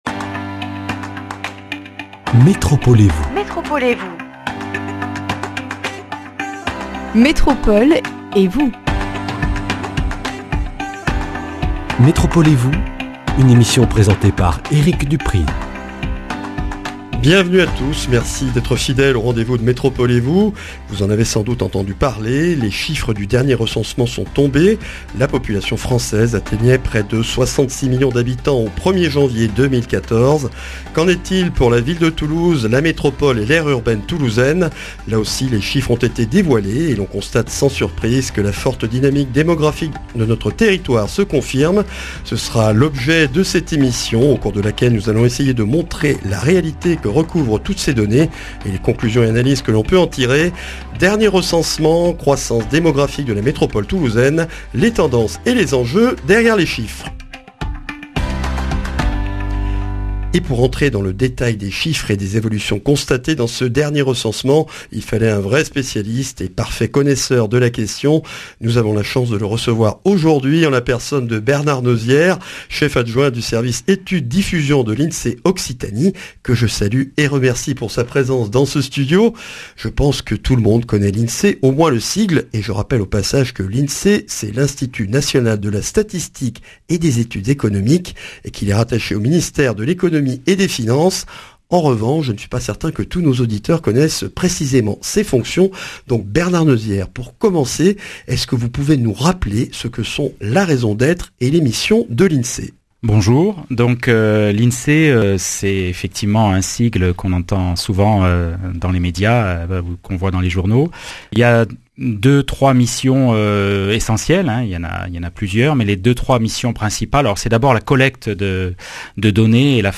Speech Dernier recensement, croissance démographique de la métropole... les tendances et les enjeux derrière les chiffres.